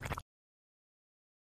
Mud Blurp Quick